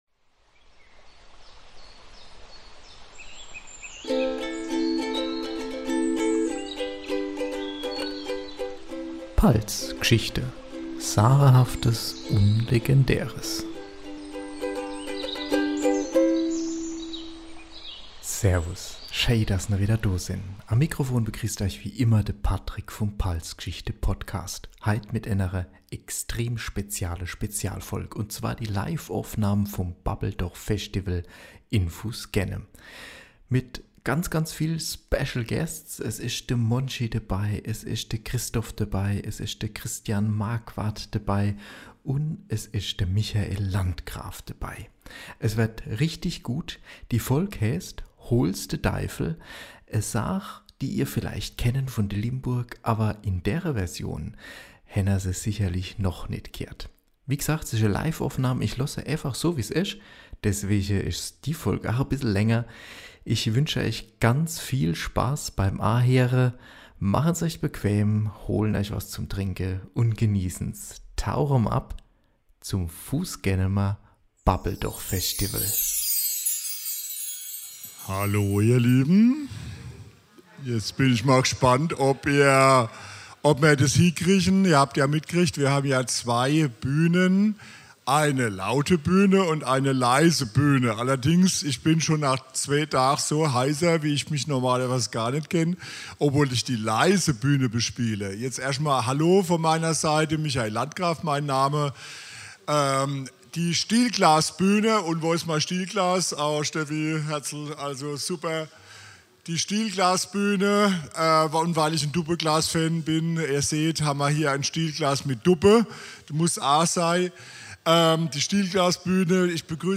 Hier kommt endlich die Live-Aufnahme vom Babbel Doch Festival in Fußgönheim.